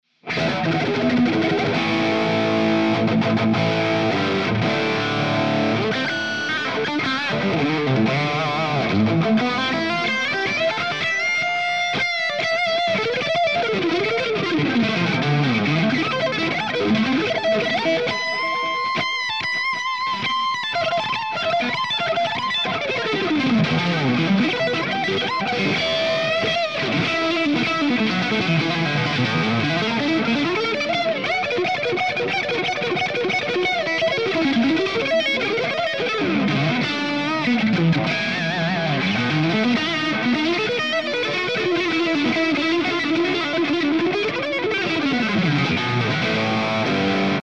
This one does it with great balance and alot of bite!
Improv
RAW AUDIO CLIPS ONLY, NO POST-PROCESSING EFFECTS
Hi-Gain